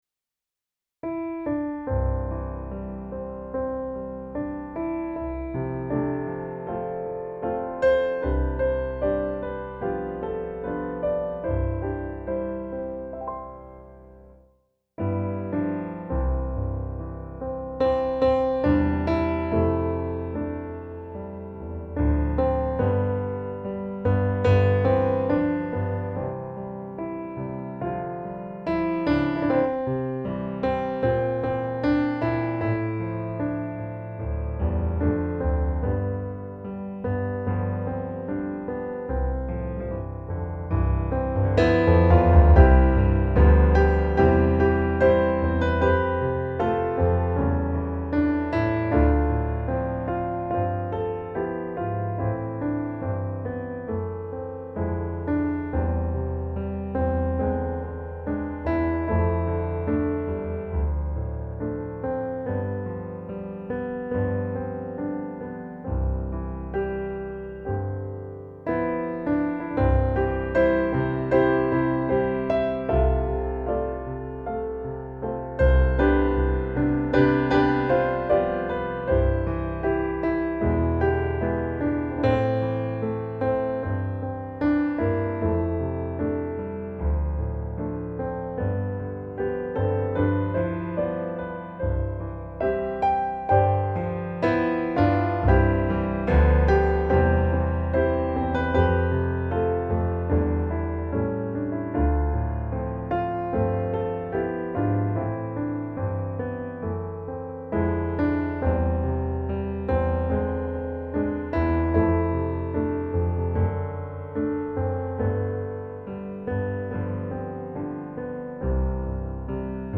Gemensam sång
Musikbakgrund Psalm